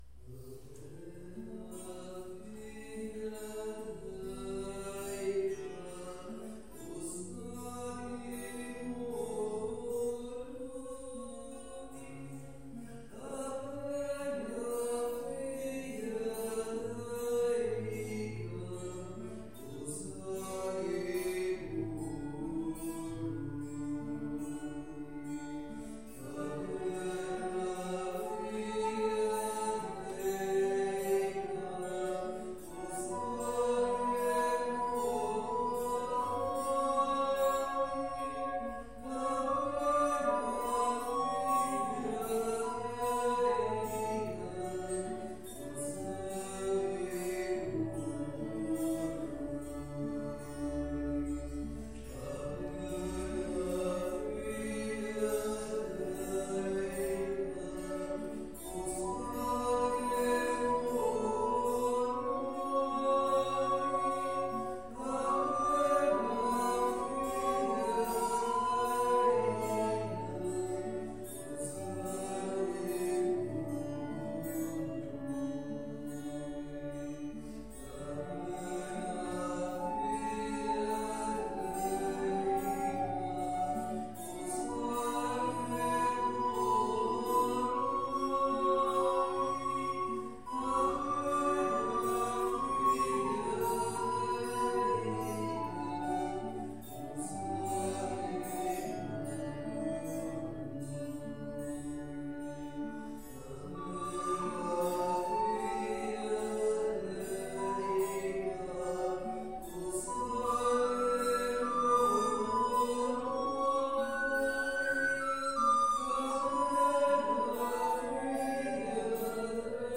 Pregària de Taizé a Mataró... des de febrer de 2001
Ermita de Sant Simó - Divendres 19 de gener de 2024